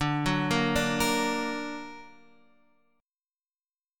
D+ chord